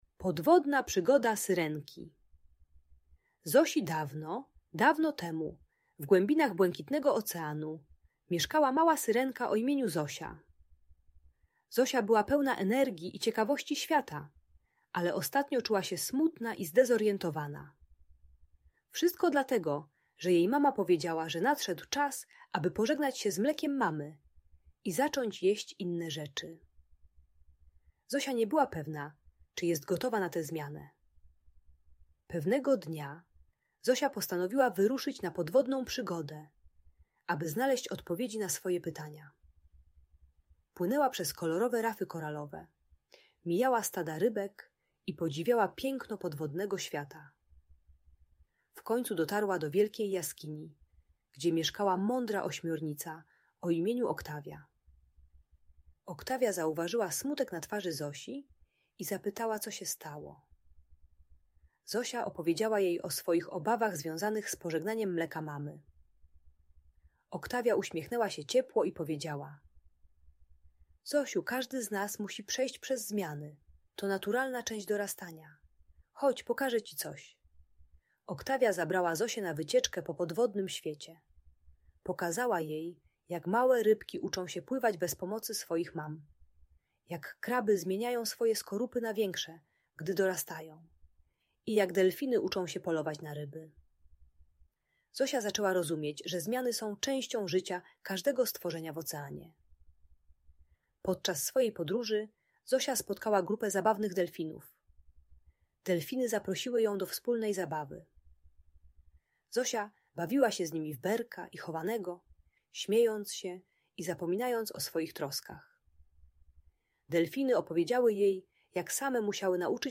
Podwodna Przygoda Syrenki Zosi - Audiobajka dla dzieci